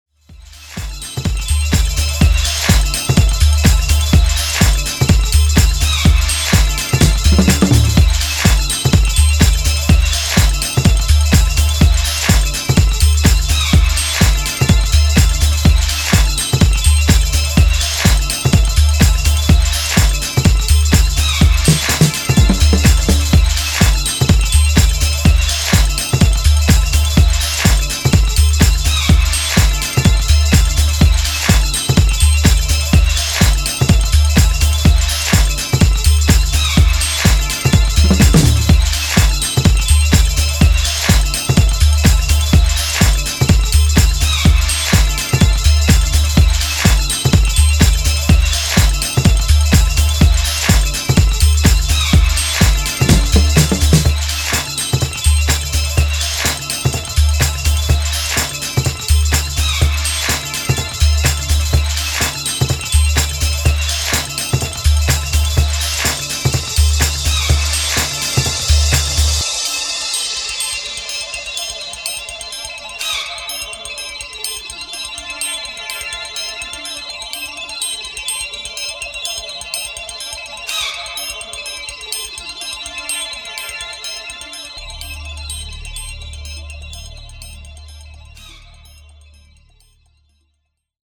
groovy, Chicago-inspired signature style
Remixed in Mondo Studio Rome 2023